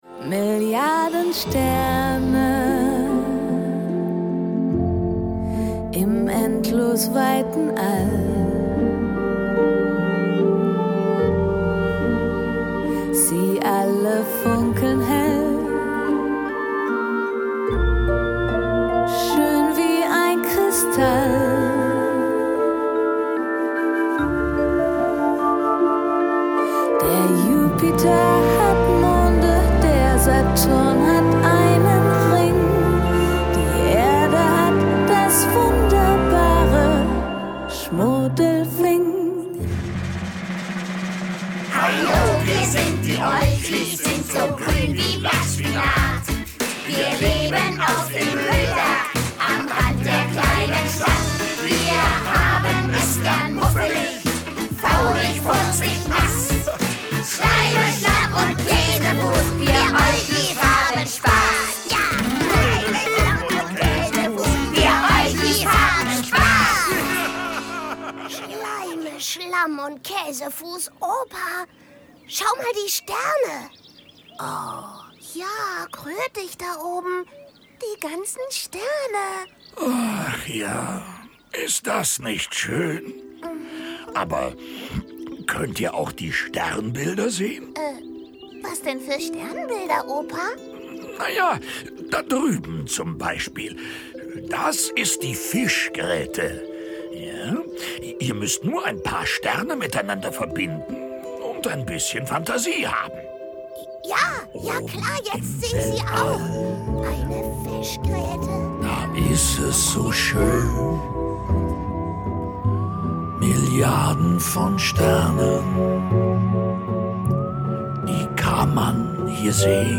Hörbuch: Die Olchis.